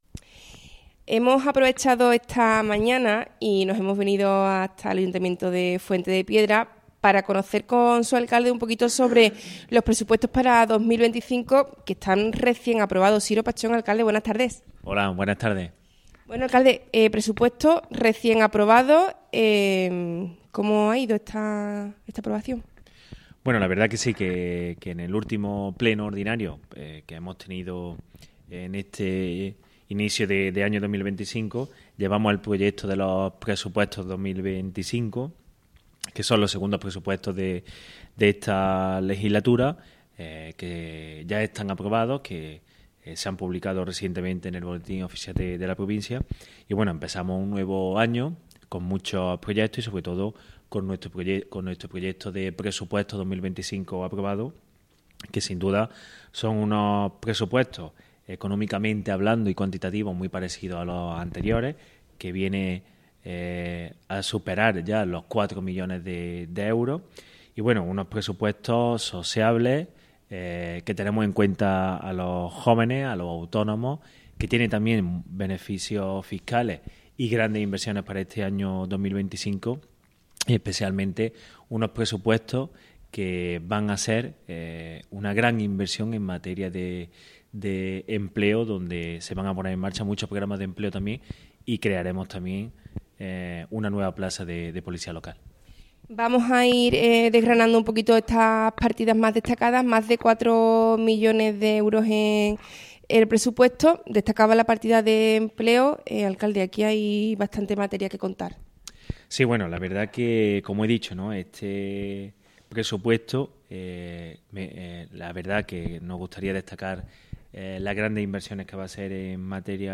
Entrevista Siro Pachón. Presupuestos Fuente de Piedra 2025.